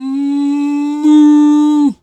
cow_2_moo_06.wav